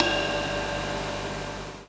Closed Hats
Ride_2.wav